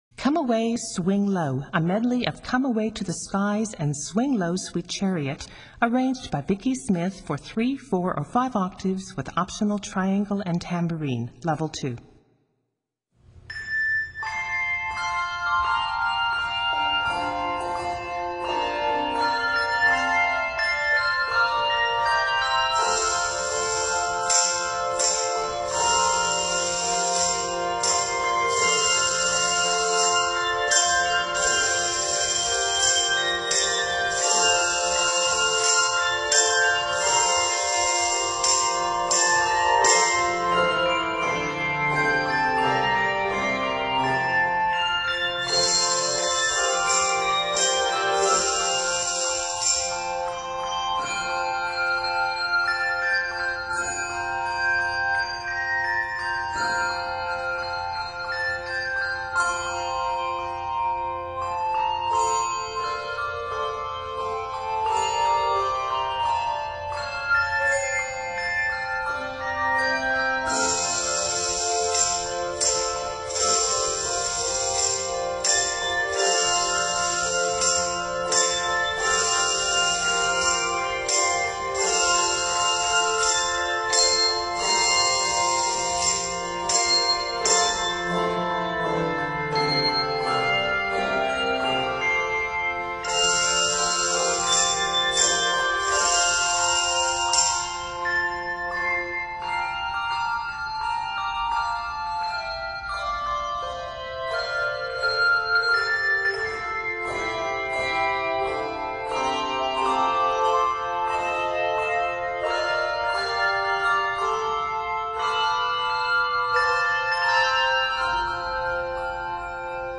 Optional tambourine and triangle add a festive touch
an American folk hymn